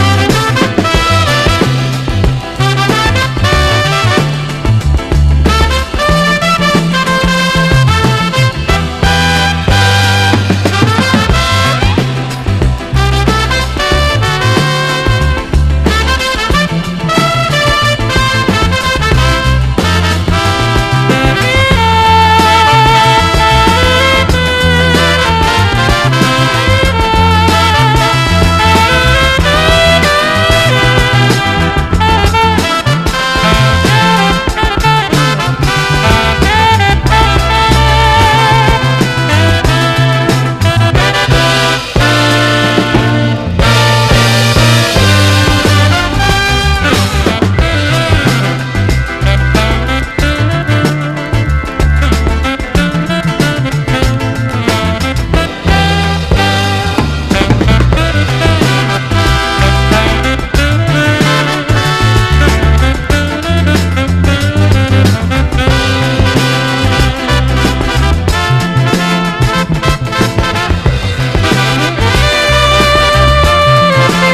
¥1,880 (税込) ROCK / SOFTROCK.
シュビドゥビ・スキャットも華麗なスウィンギン・ソフトロックです！